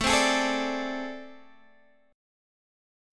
AmM7bb5 chord